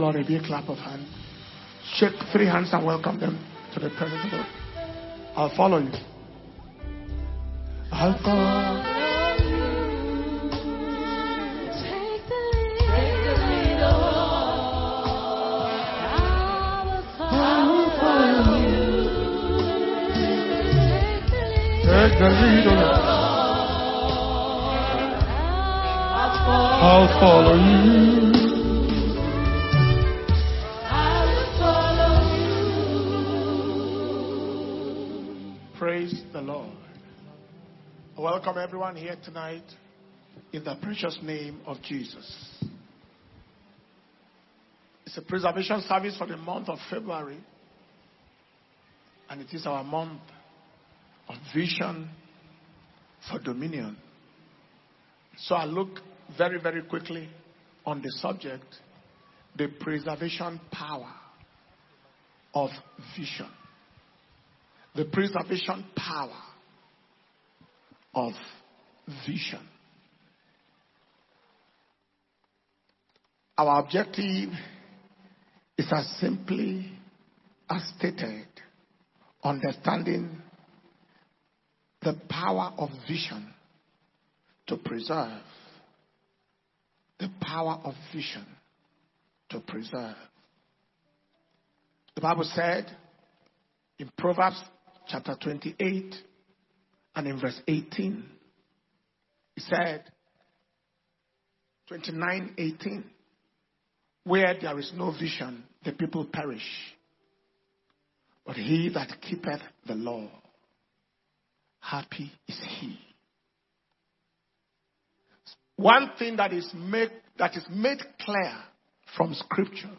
February 2025 Preservation And Power Communion Service